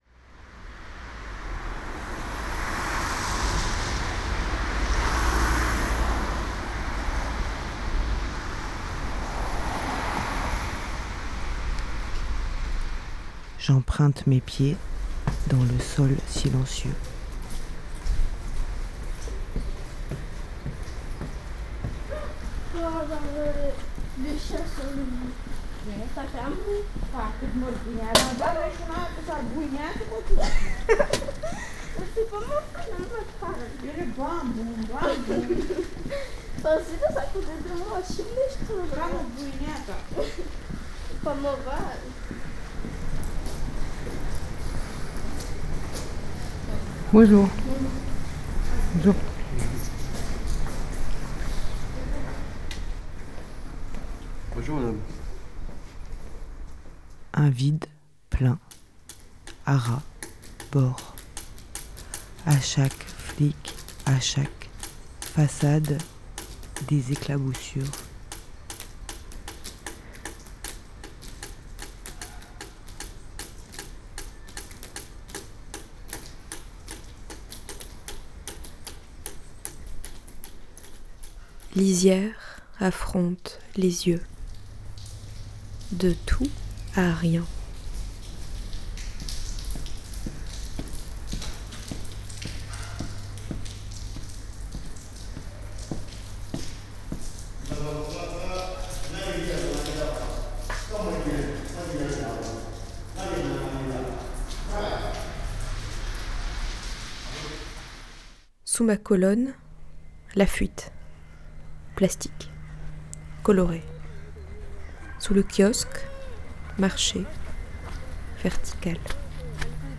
Captation et création sonore
Partir en dérive , les pieds marchent, guident , les oreilles composent …
Écouter les convivialités sonores ordinaires